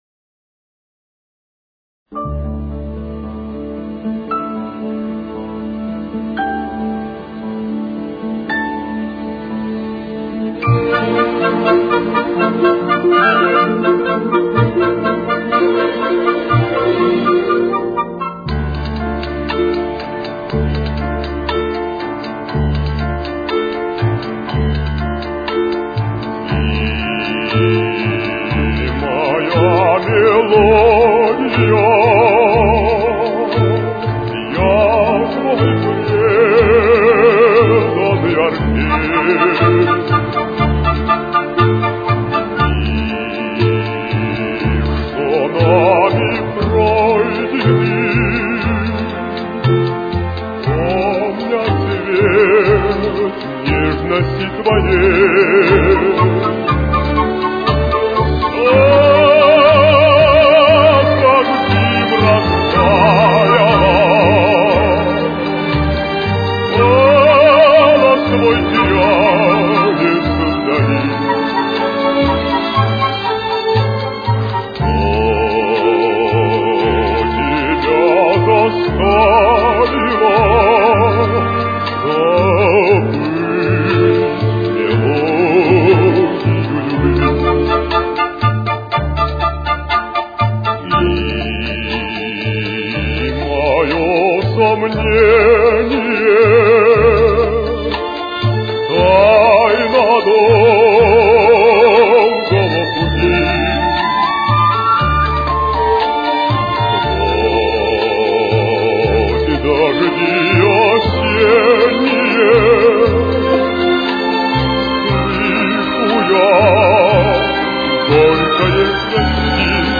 Темп: 61.